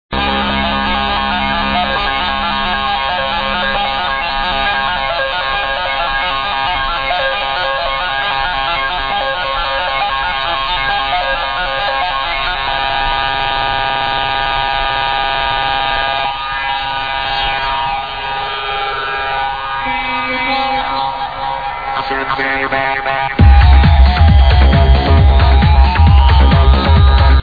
Progressive trance/tribal kinda song, please check out!